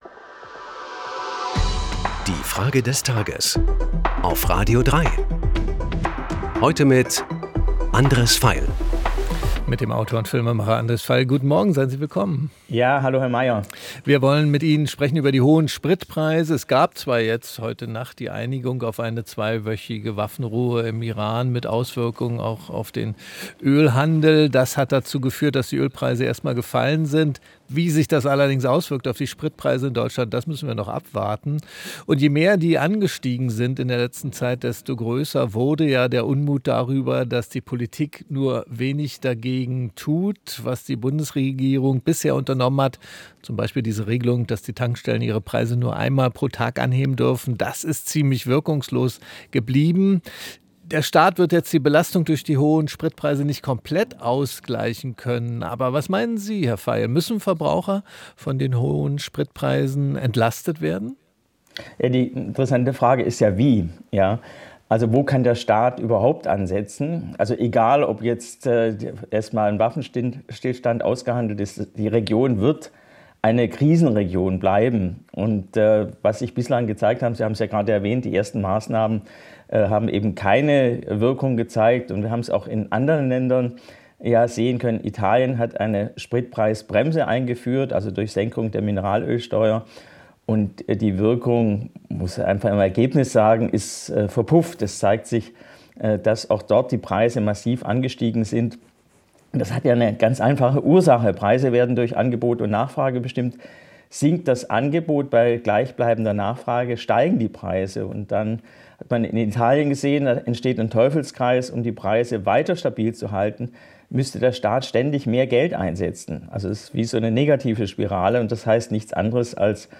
Es antwortet der Filmemacher Andres Veiel.